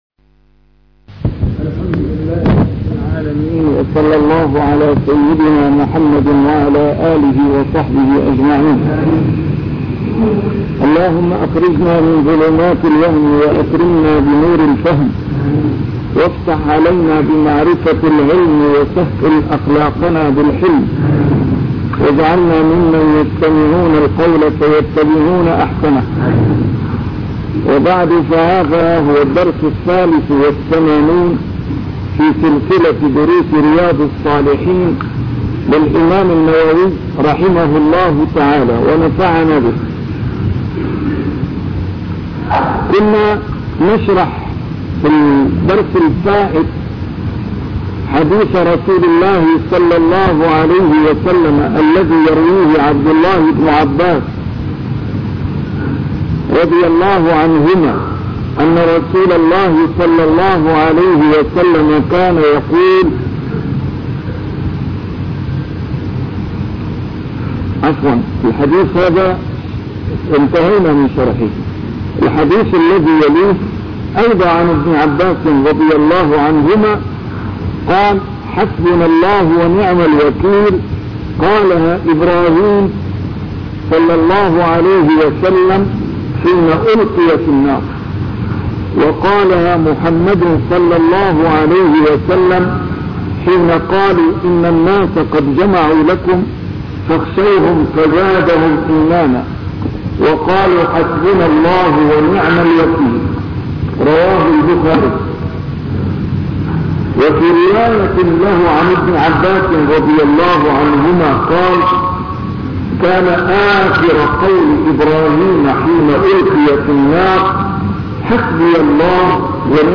A MARTYR SCHOLAR: IMAM MUHAMMAD SAEED RAMADAN AL-BOUTI - الدروس العلمية - شرح كتاب رياض الصالحين - 83- شرح رياض الصالحين: اليقين والتوكل